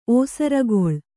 ♪ ōsaragoḷ